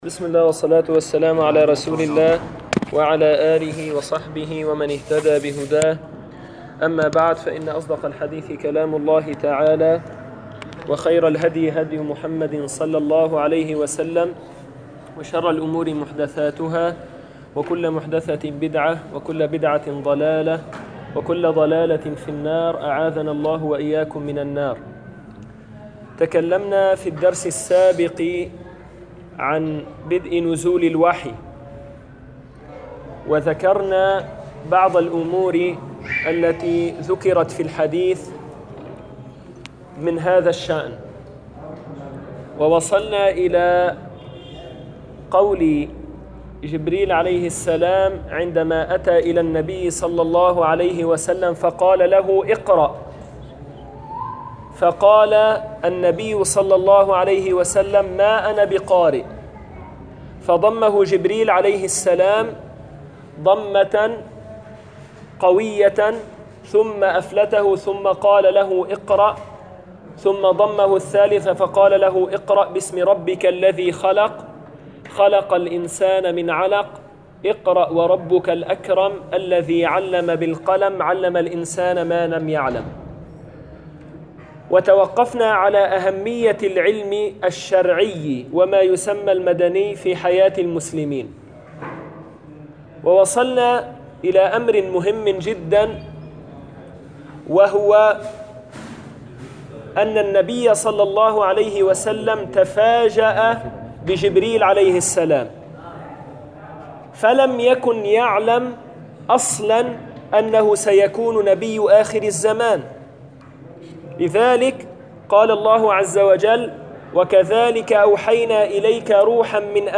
[دروس من السيرة] 07- صفات النبي صلى الله عليه وسلم
المكان: مسجد القلمون الغربي